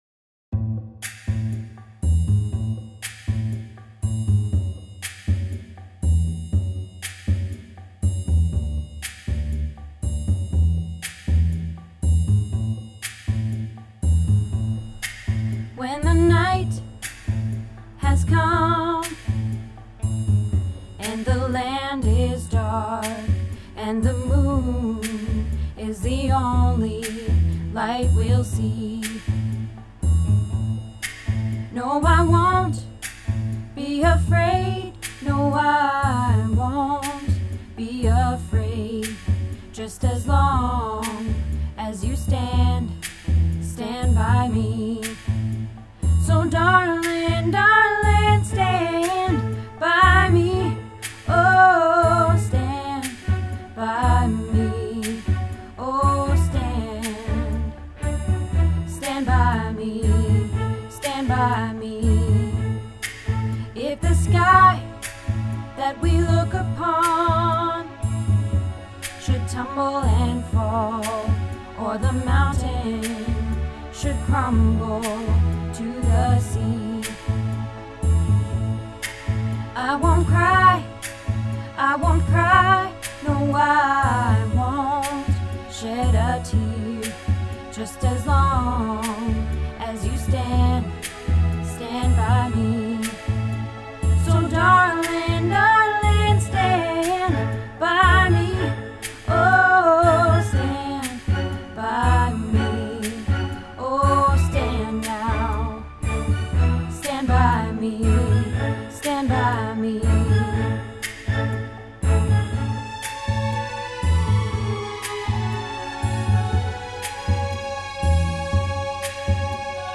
Stand By Me - Alto